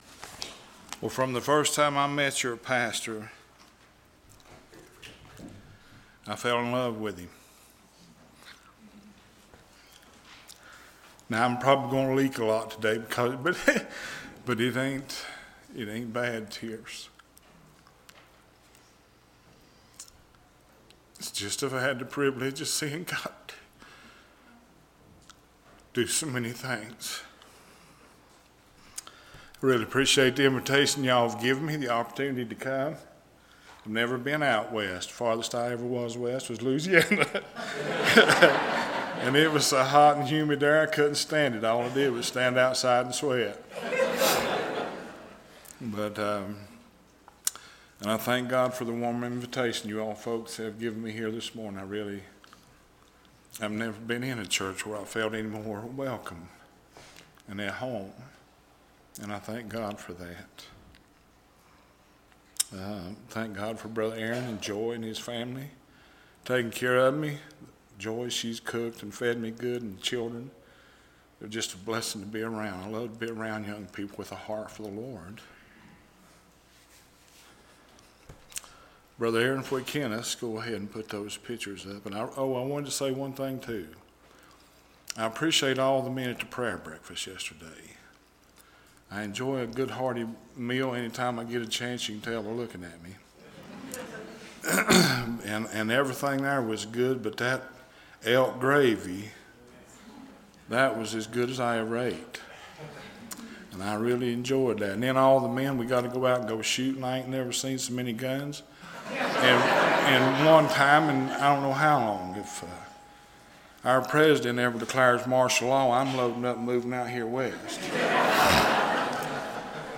September 21, 2014 – Morning Service